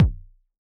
Index of /musicradar/retro-drum-machine-samples/Drums Hits/Raw
RDM_Raw_SY1-Kick01.wav